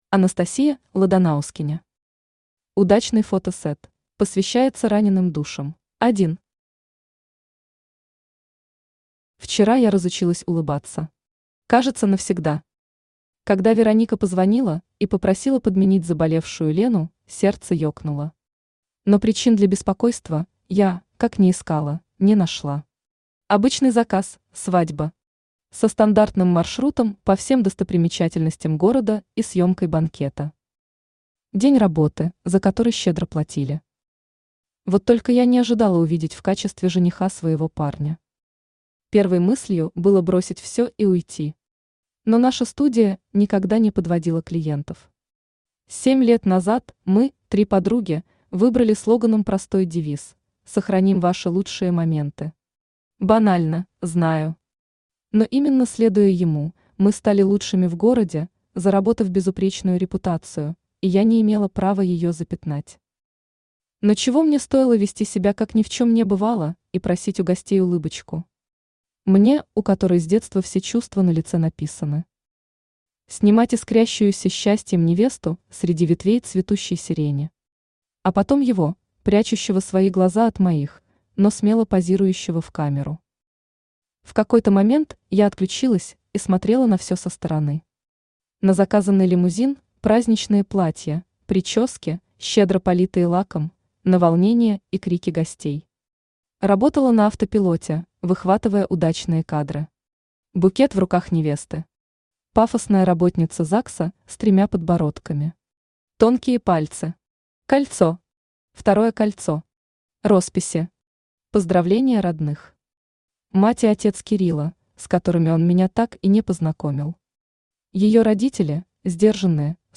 Аудиокнига Удачный фотосет | Библиотека аудиокниг
Aудиокнига Удачный фотосет Автор Анастасия Ладанаускене Читает аудиокнигу Авточтец ЛитРес.